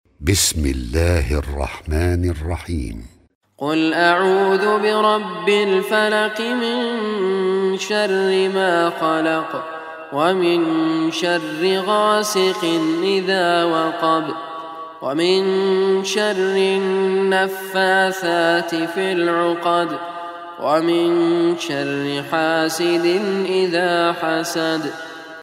Surah Al Falaq Recitation by Raad Al Kurdi
Surah Al Falaq, listen online or download mp3 tilawat / recitation in Arabic in the beautiful voice of Sheikh Raad Al Kurdi.